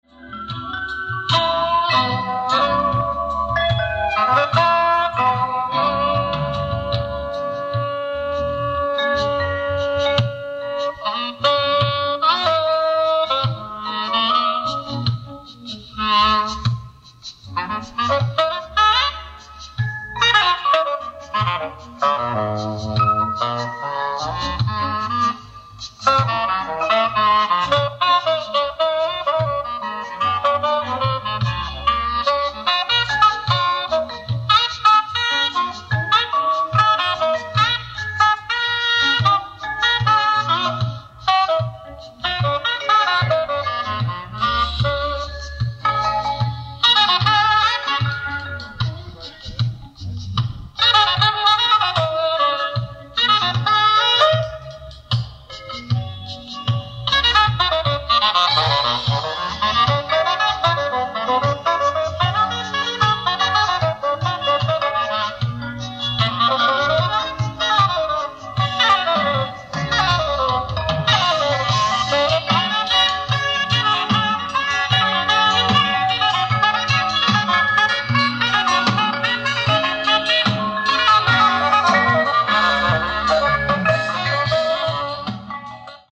ライブ・アット・札幌、北海道 07/26/1986
※試聴用に実際より音質を落としています。